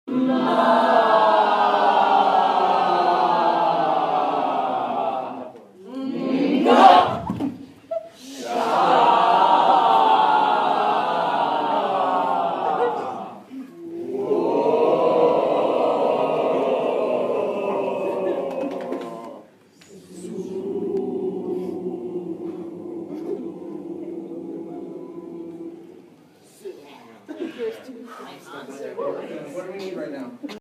Field Recording
Location: Lowe 106, Peer Gynt rehearsals, 2/25/16, 7PM
Sounds: Actors warming up their voices
Warm-Ups-For-Peer-Gynt.mp3